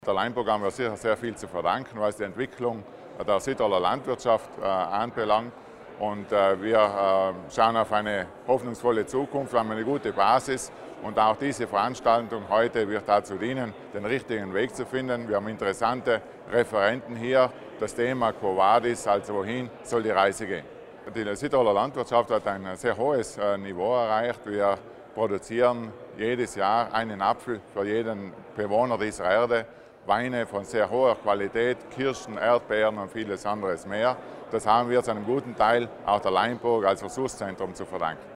180 Personen hatten sich zum Festsymposium anlässlich des 40-jährigen Bestehens des Land- und Forstwirtschaftlichen Versuchszentrums Laimburg in der Aula Magna in Auer angemeldet. Dabei wurde heute (21. November) Rückschau auf das Erreichte und Ausblick auf neue Herausforderungen gehalten.